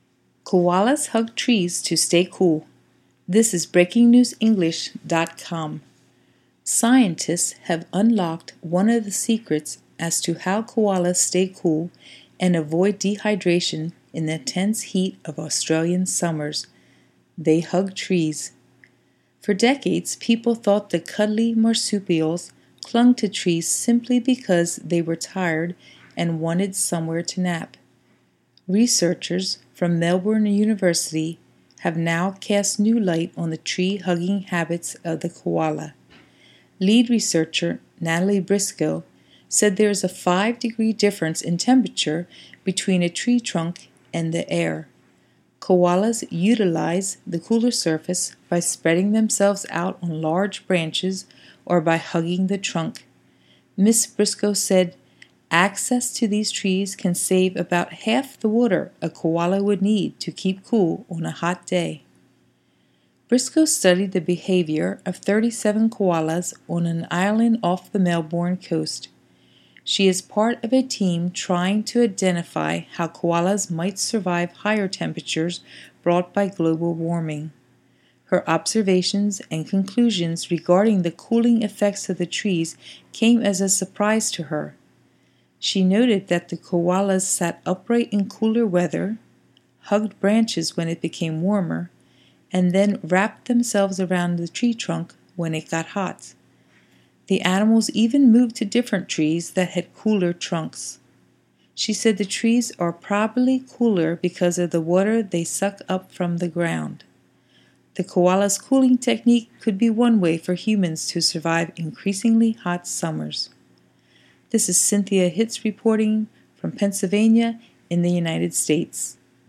Would you try this idea to stay cool?  (also, here is a British speaker)